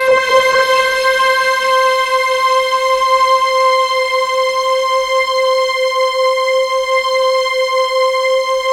POLYSYNTC5-R.wav